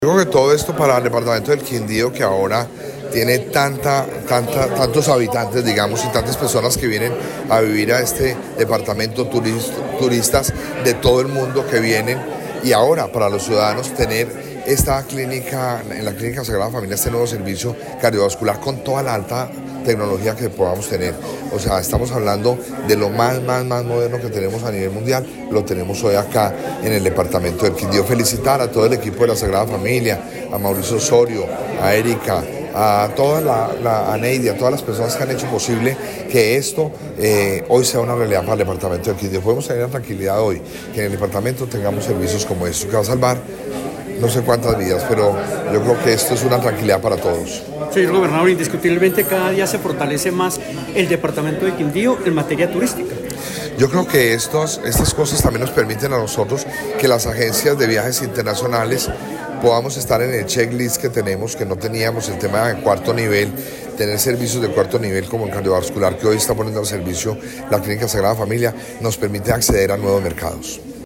Audio de: El Gobernador del Quindío, Roberto Jairo Jaramillo, inauguración unidad cirugía cardiovascular
Audio-gobernador-del-Quindio-Roberto-Jairo-Jaramillo-inauguracion-unidad-cirugia-cardiovascular.mp3